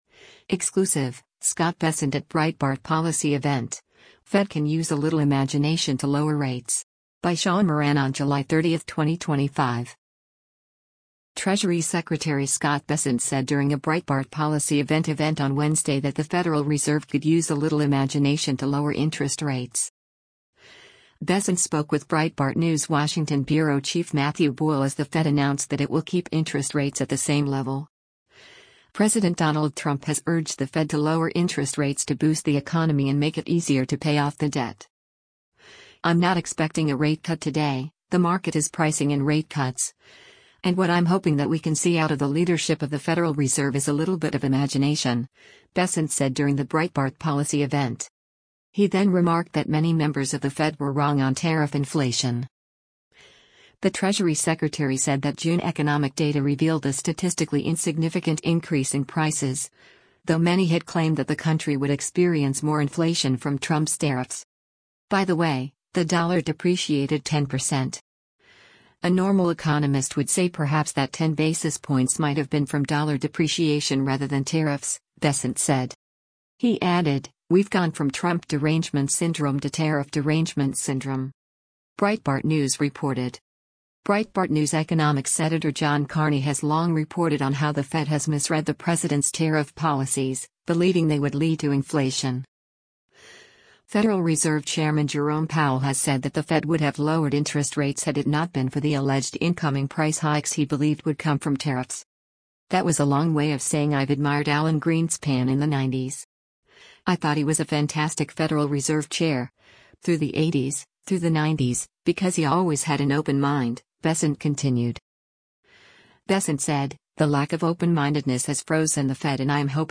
Exclusive--Scott Bessent at Breitbart Policy Event: Fed Can Use a 'Little Imagination' to Lower Rates
Treasury Secretary Scott Bessent said during a Breitbart policy event event on Wednesday that the Federal Reserve could use a “little imagination” to lower interest rates.